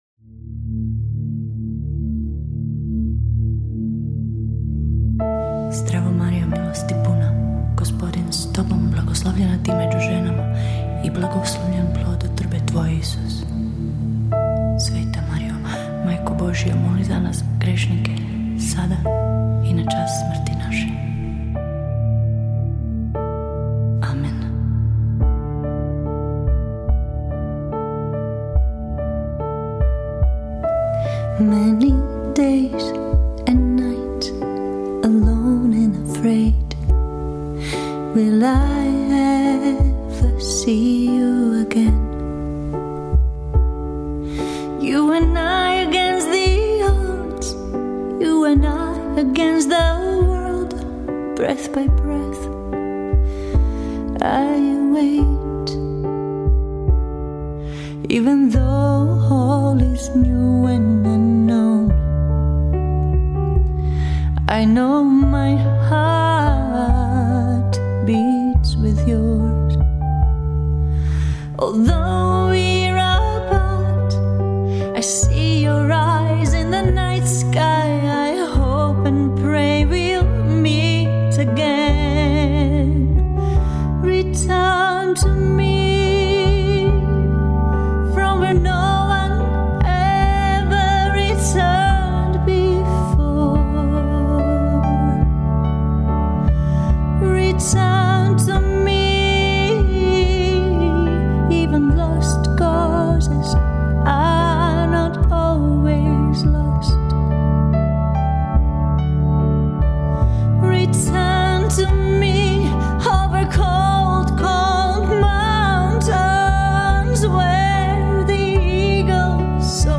Home > Music > Pop > Smooth > Medium > Hopeful